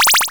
levelup.wav